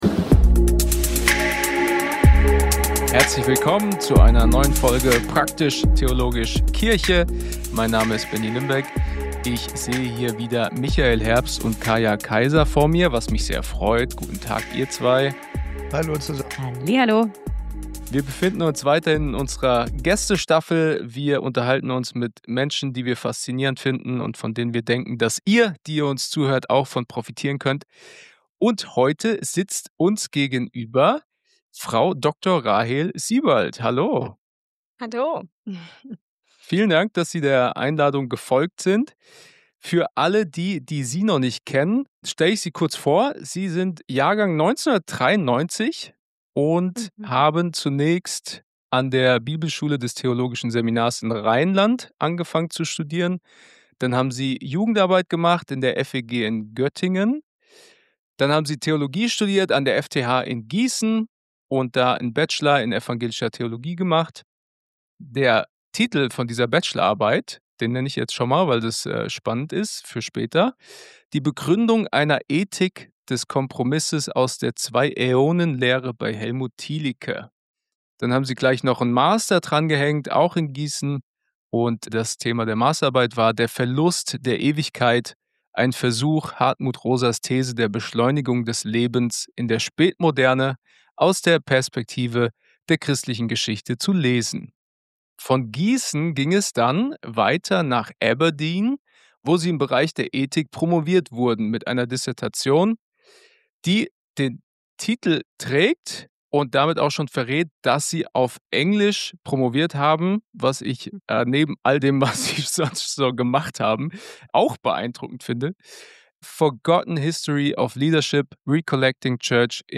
Wir befinden uns in unserer zweiten Staffel, in der wir mit faszinierenden Gästen ins Gespräch kommen! Wir reden mit ihnen über das, was sie begeistert und wo sie Chancen und neue Perspektiven für die Kirche sehen.